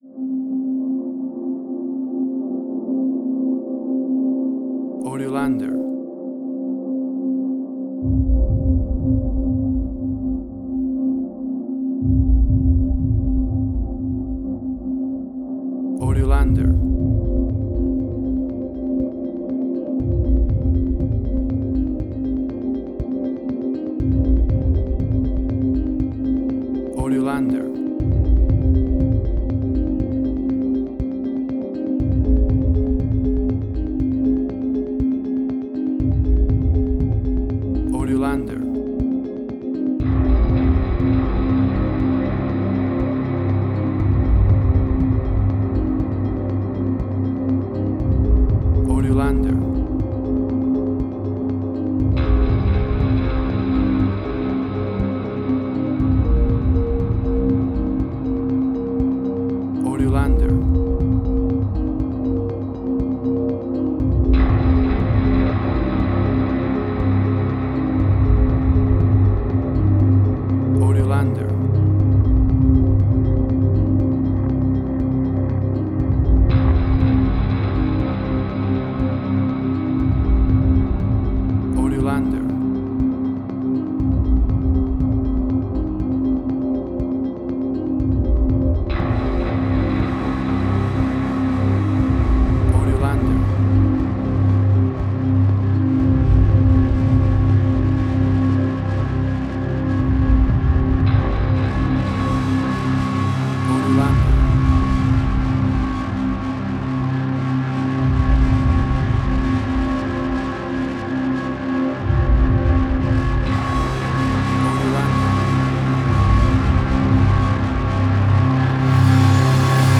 Post-Electronic.
WAV Sample Rate: 16-Bit stereo, 44.1 kHz
Tempo (BPM): 120